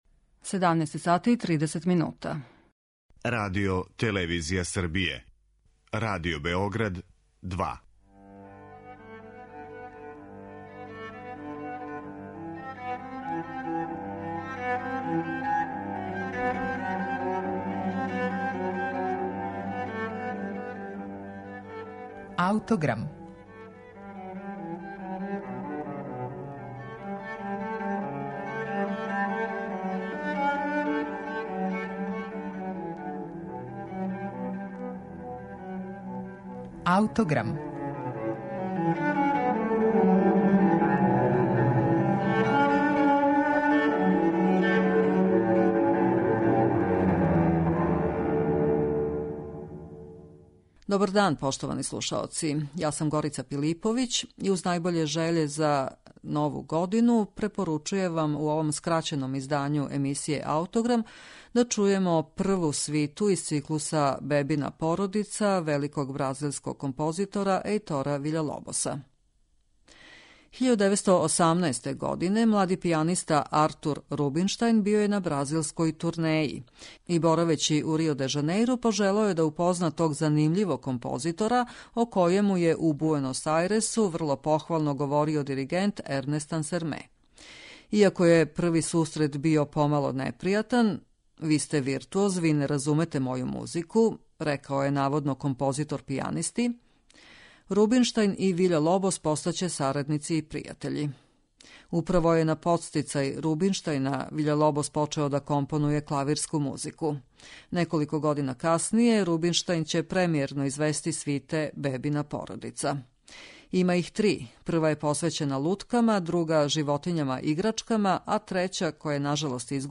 прву свиту комада за клавир
Бебина породица, поред тога што је израз личног стила аутора, представља и први етнографски документ с обзиром на то да је Виља-Лобос употребио бразилске народне мелодије.